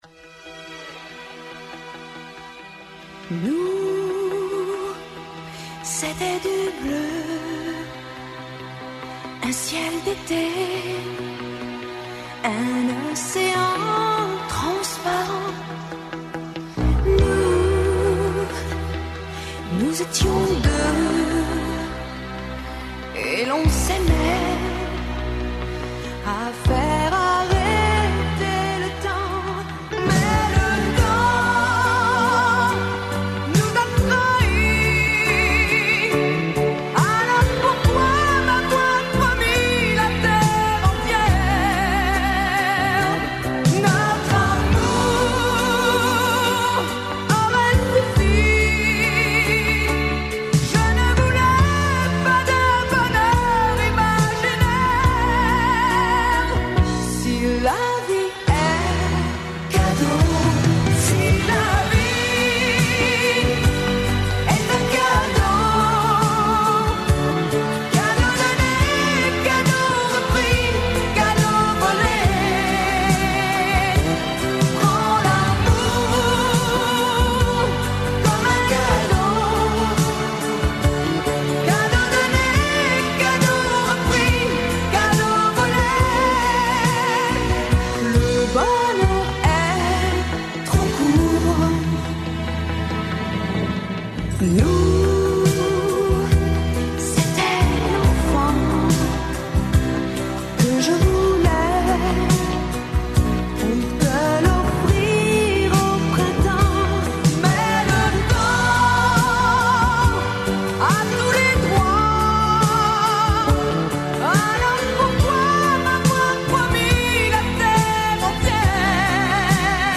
Чућемо какво је расположење и каква су очекивања у екипи Србије уочи суботњег финала Евросонга. Настављамо да преслушавамо победничке песме из претходних година.